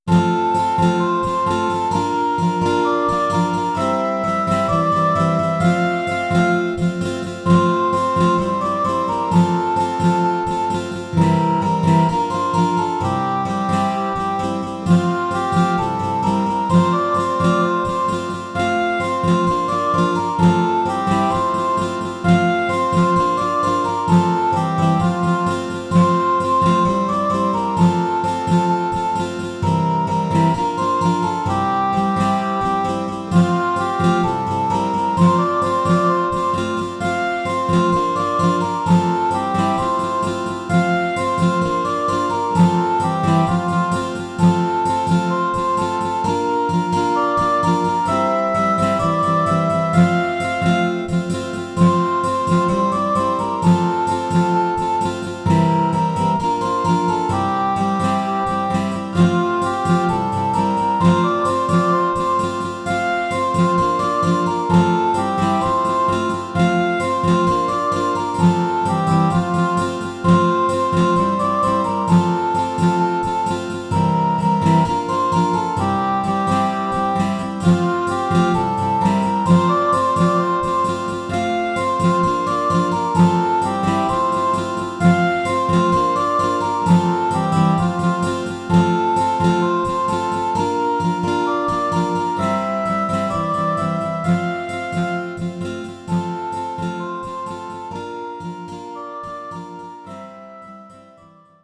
GITARREN-AKKORDE